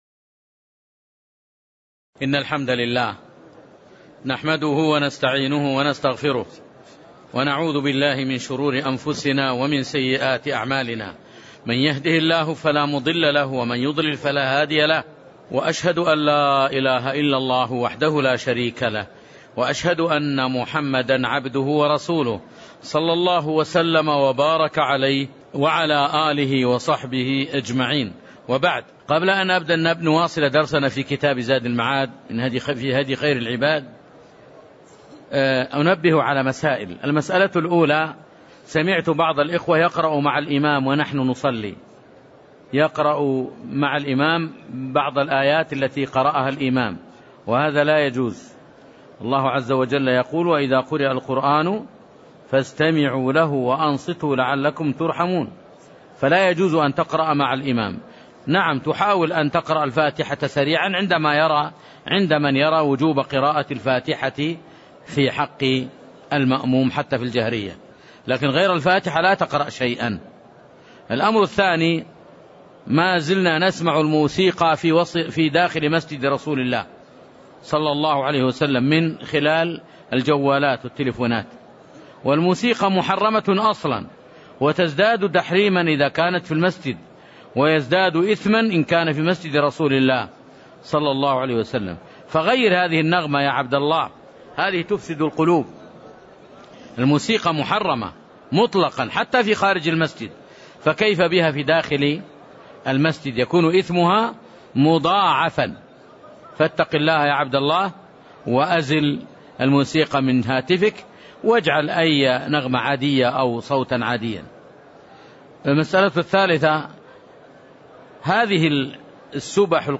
تاريخ النشر ٤ ذو الحجة ١٤٣٦ هـ المكان: المسجد النبوي الشيخ